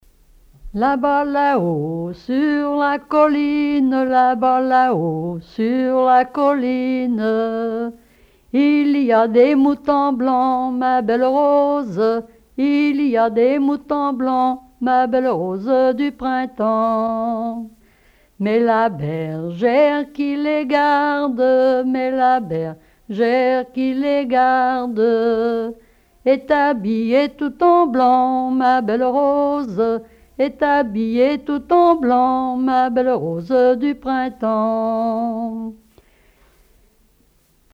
collecte en Vendée
Témoignages et chansons traditionnelles
Pièce musicale inédite